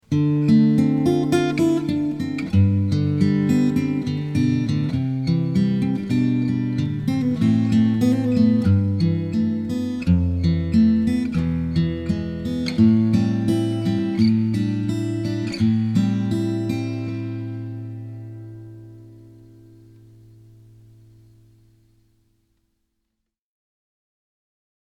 Just recorded with two condensor mics and a hint of reverb.
A jumbo with cutaway.
Red Cedar top ( about 2,8 mm thick)
Black Walnut back/sides